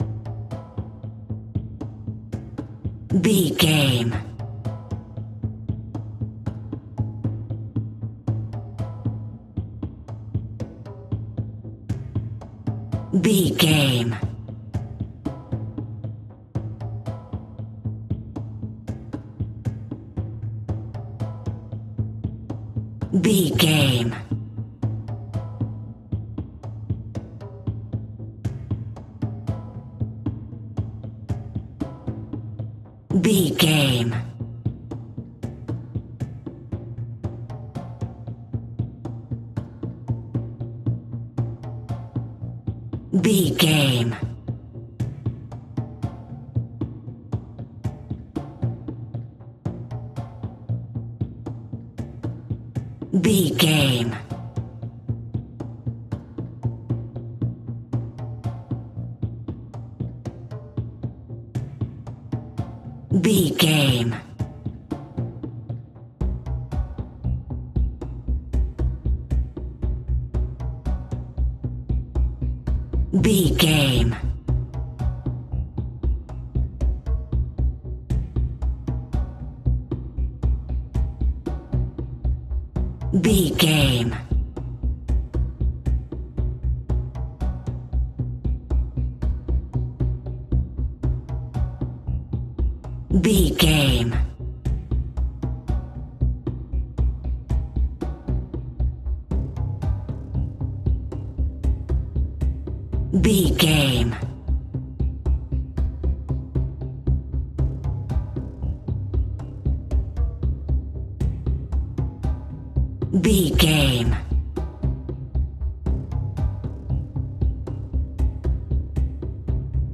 In-crescendo
Thriller
Aeolian/Minor
tension
ominous
dark
haunting
eerie
percussion
conga
synthesiser
mysterious